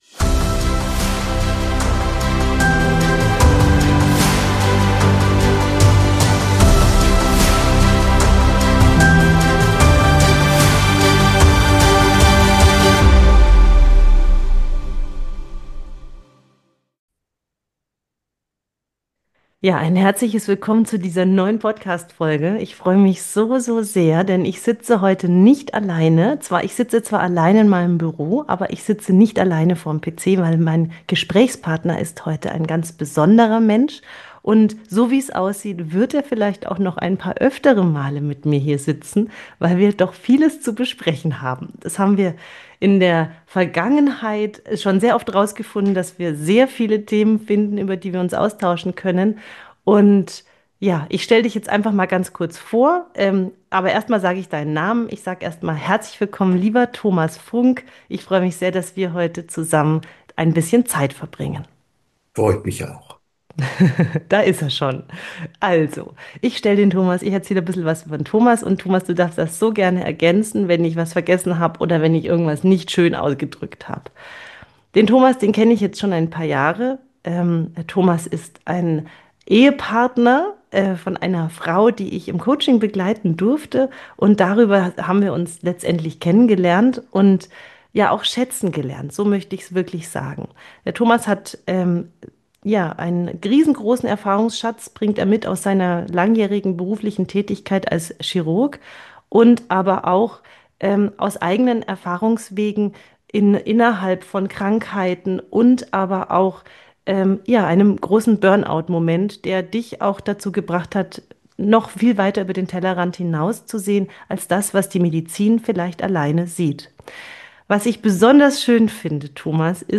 Ein Gespräch zwischen zwei Generationen, ehrlich, reflektiert, humorvoll und überraschend lebensnah.
Sie ist ein echtes Gespräch.